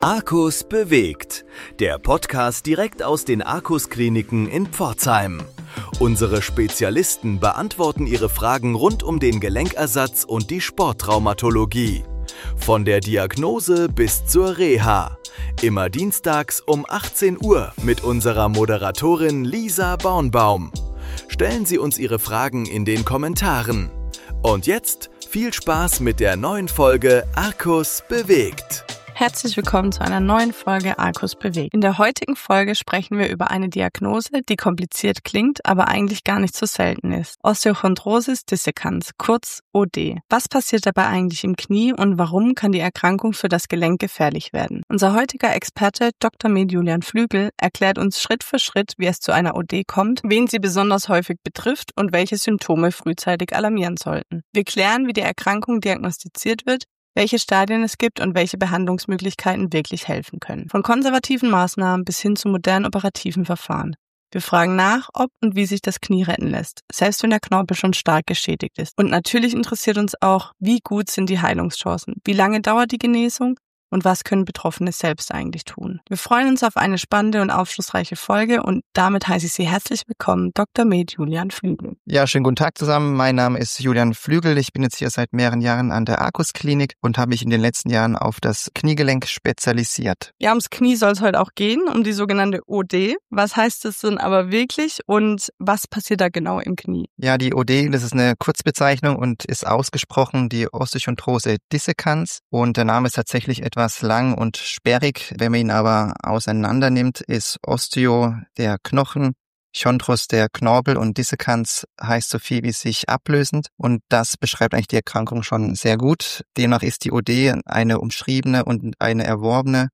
Im Verlauf des Gesprächs werden spezifische Patientengruppen beleuchtet - insbesondere Kinder und Jugendliche im Alter von 10 bis 20 Jahren, die aktive Sportler sind.